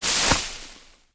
Sound when placing units and buildings;
Light  Grass footsteps 3.wav